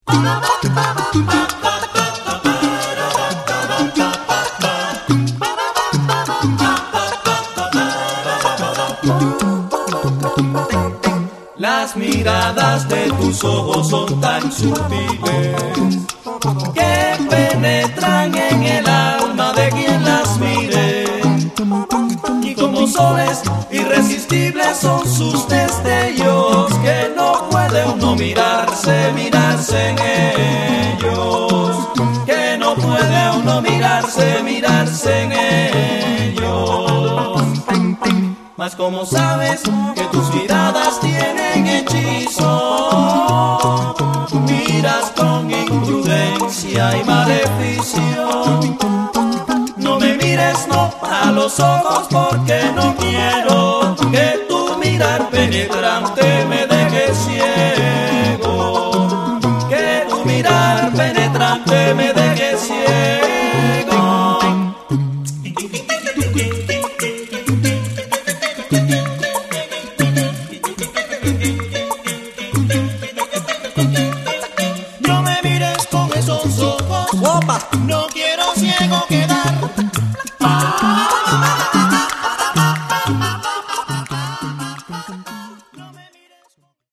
Cubani.
Le percussioni infatti sono sbalorditive.